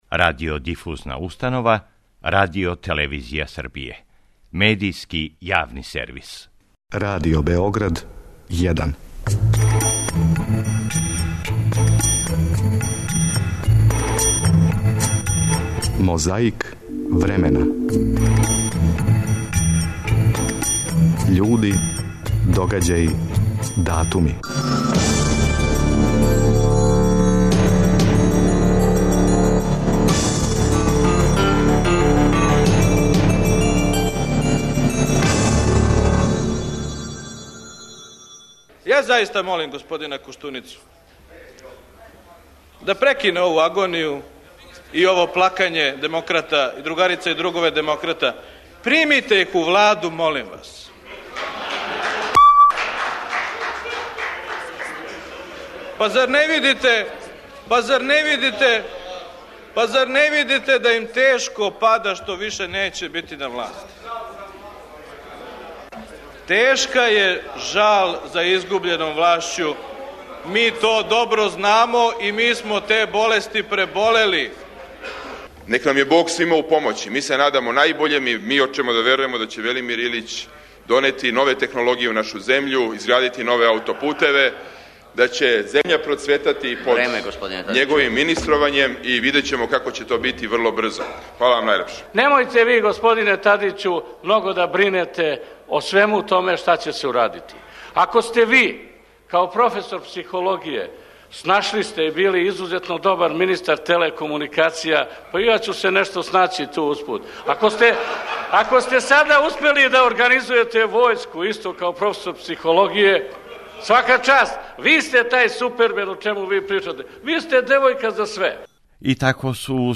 - Седми самит несврстаних у Њу Делхију, 7. 3. 1983., говори Индира Ганди